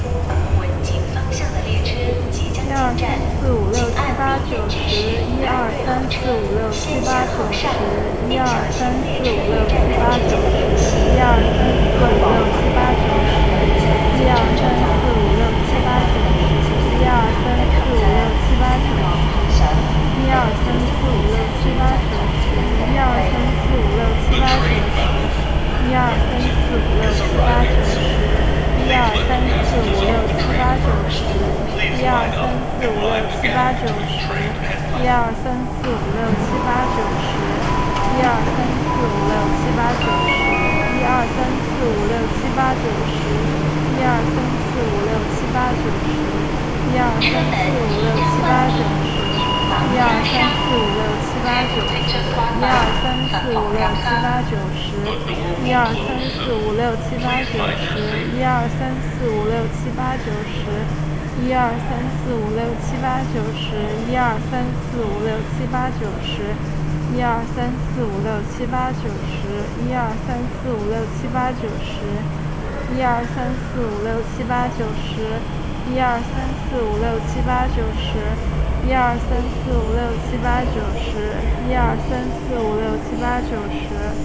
The recording was made with real equipment (two omnidirectional microphones with a distance of about 2 cm) in a real Chinese subway station.
At the same time, we can hear the platform announcement, train running, braking, door opening, door closing and other ambient sounds.
metro_recording.wav